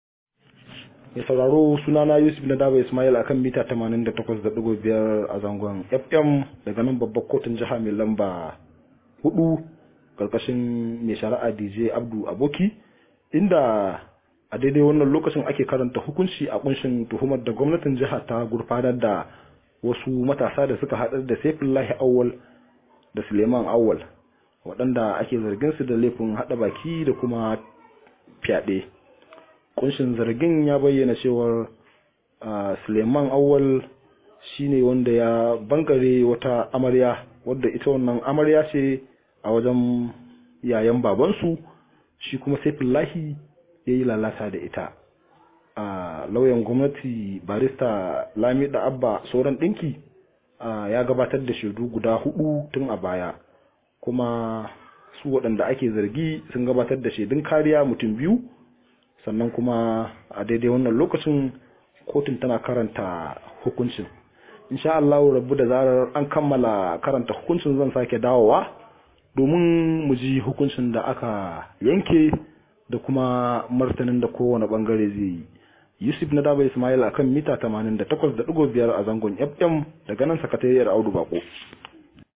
Rahoto: Kotu na zargin wasu matasa da yi wa amarya fyade